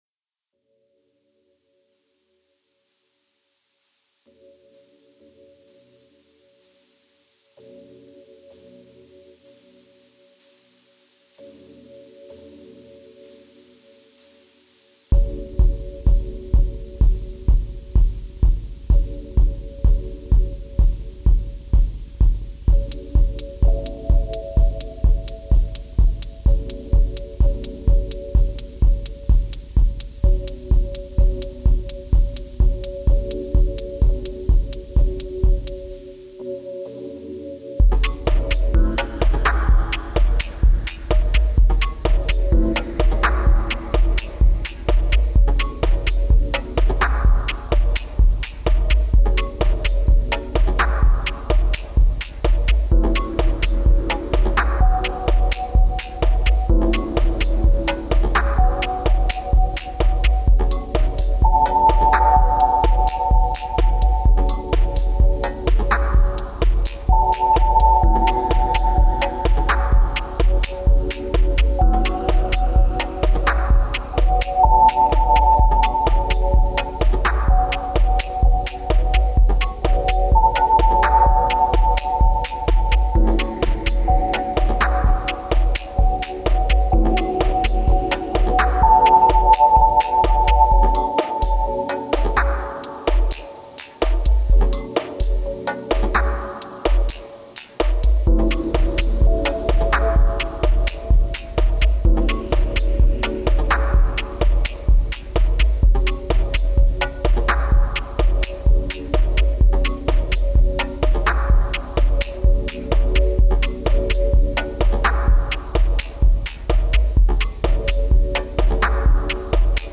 Genre: Deep House/Dub Techno.